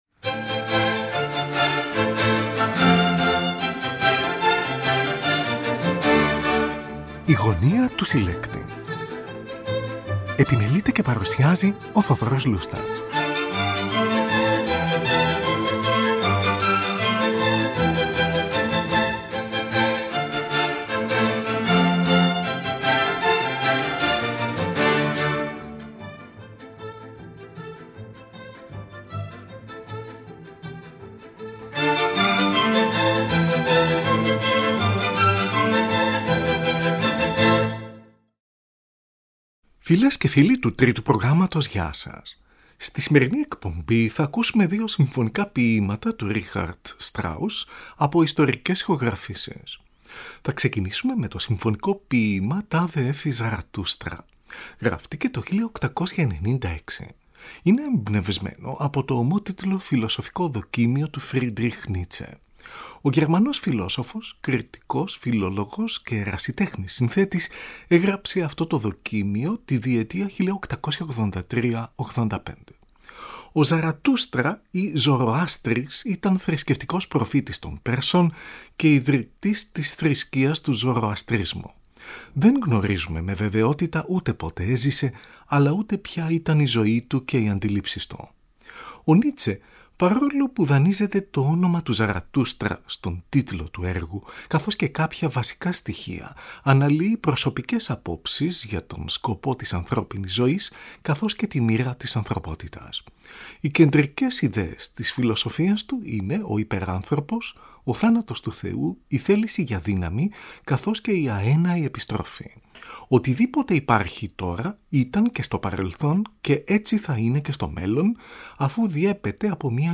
για μεγάλη ορχήστρα